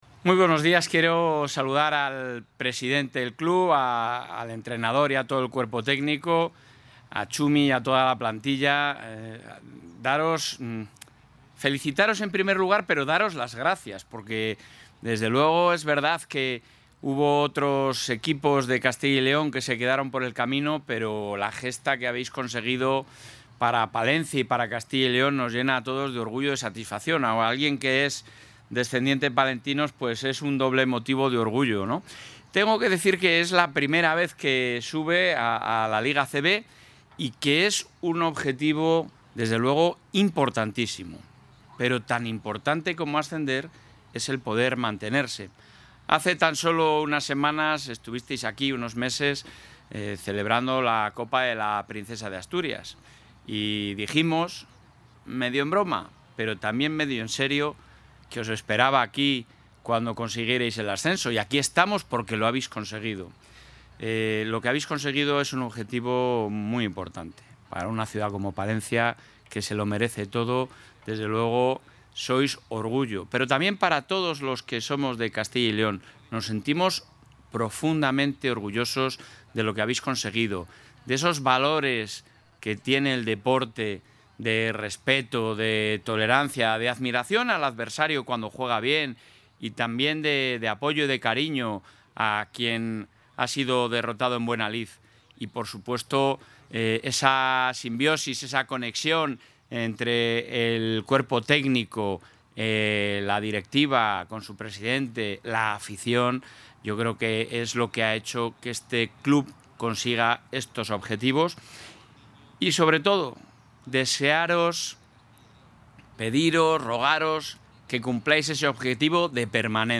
Intervención del presidente de la Junta.
El presidente de la Junta de Castilla y León ha recibido a los miembros del club palentino tras su subida a la máxima categoría del baloncesto español.